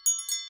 Footsteps
bells5.ogg